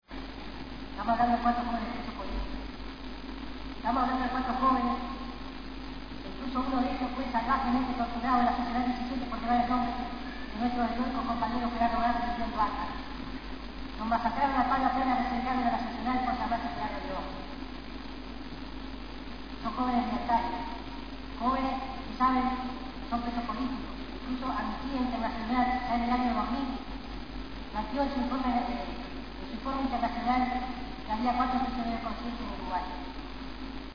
A continuación extractos grabados por Indymedia/Uruguay de las palabras dichas por